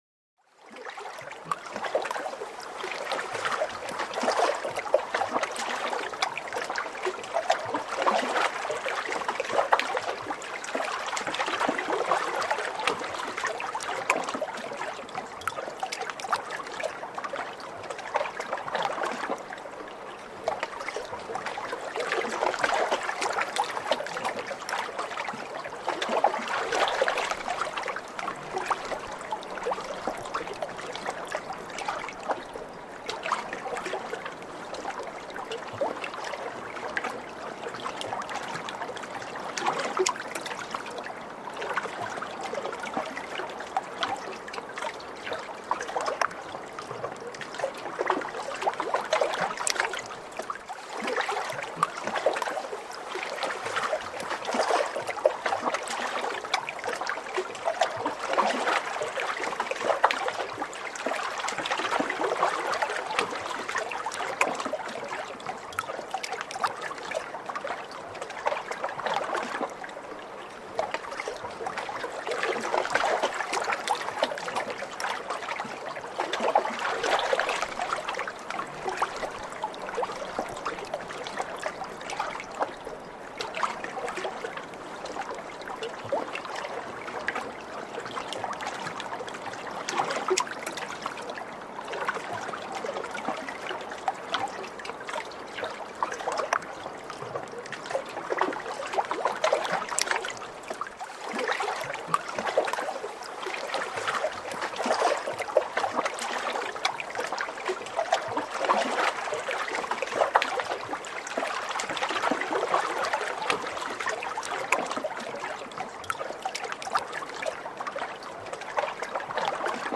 FRIEDEN-FLÜSTERER: Gletschersee-Mondlicht - Eis atmet, Wasser flüstert